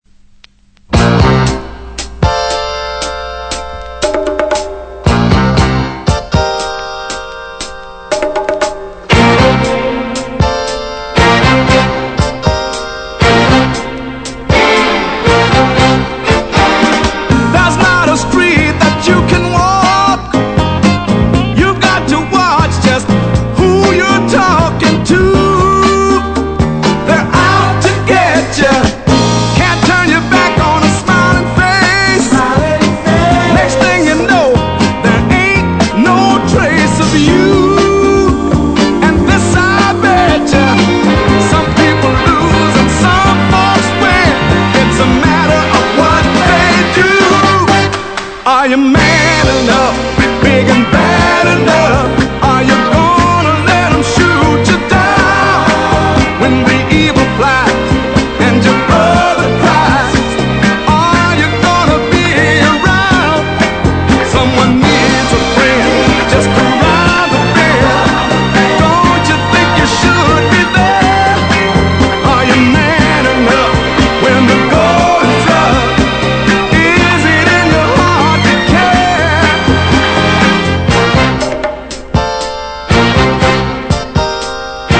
Genre: SOUL ORIG / REISS